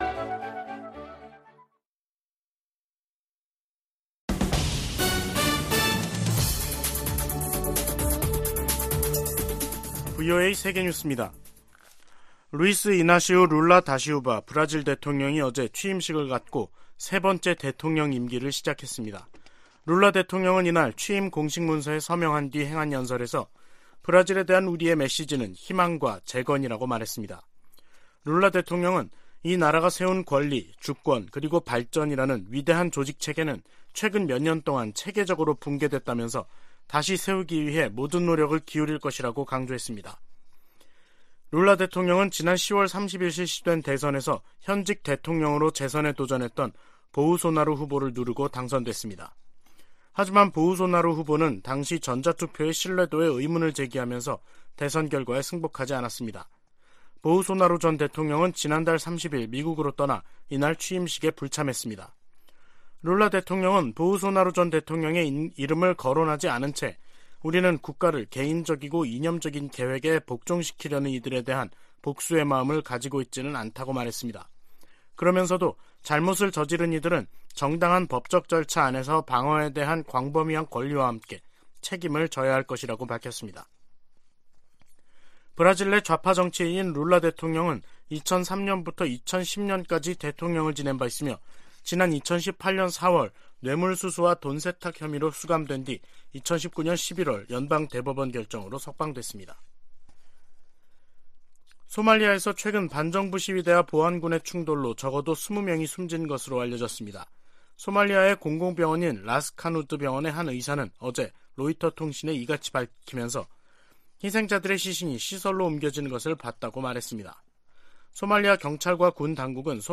VOA 한국어 간판 뉴스 프로그램 '뉴스 투데이', 2023년 1월 2일 2부 방송입니다. 미국 국무부가 이틀 연속 탄도미사일을 발사한 북한을 비판했습니다.